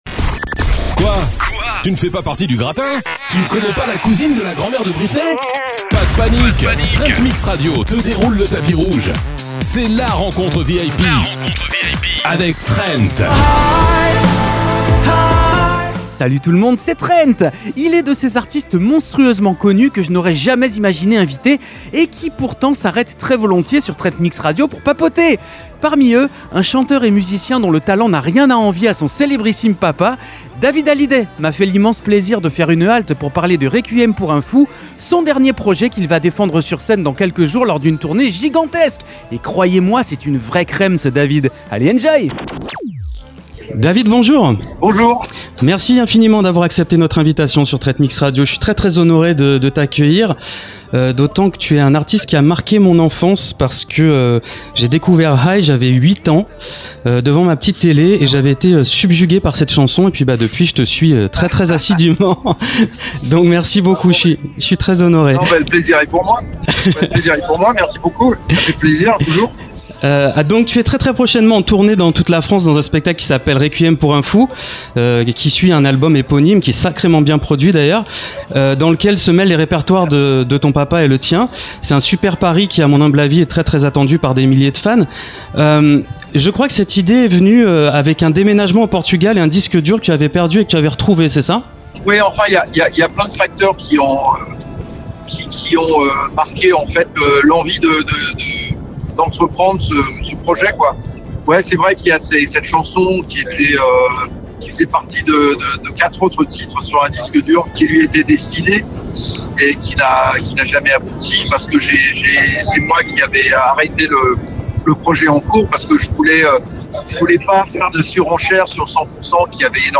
David Hallyday : l'interview !